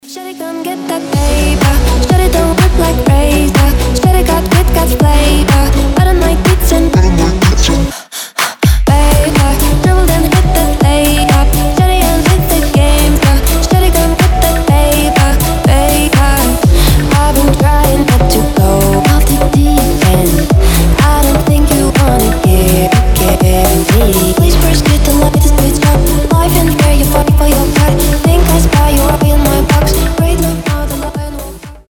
• Качество: 320, Stereo
deep house
чувственные
красивый женский голос
house